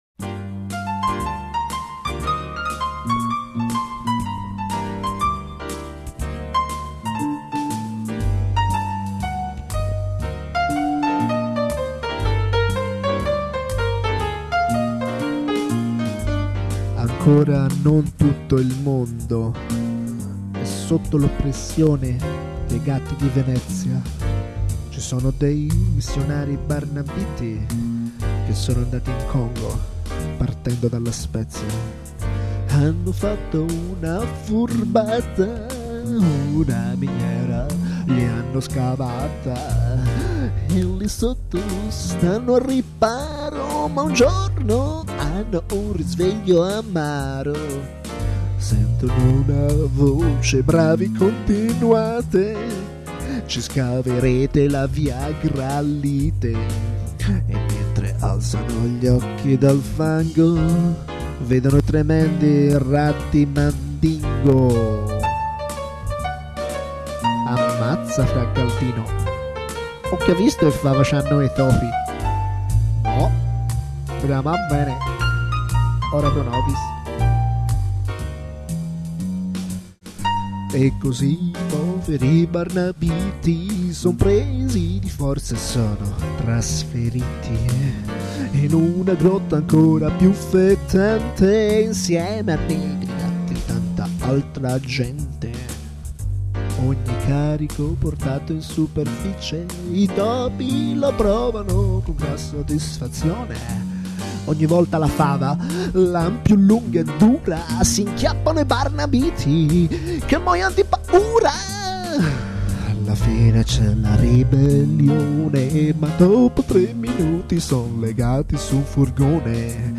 Una canzone Jazz